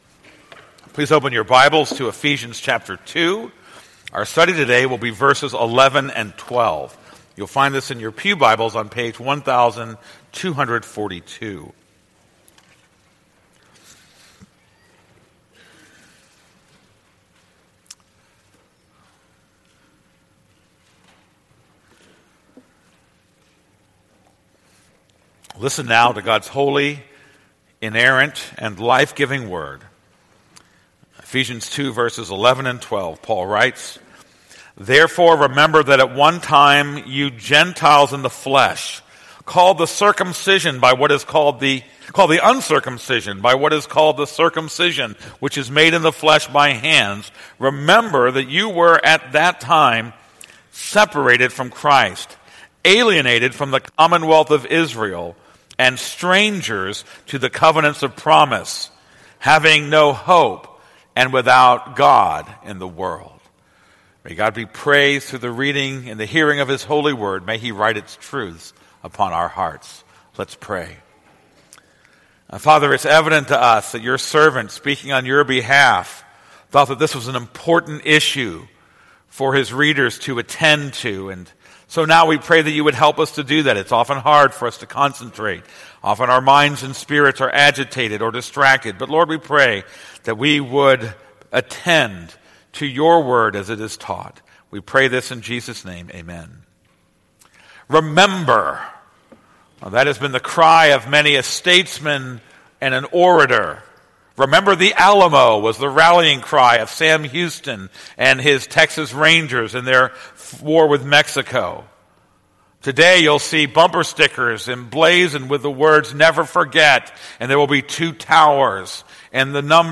This is a sermon on Ephesians 2:11-12.